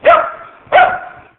Sound-of-dog.mp3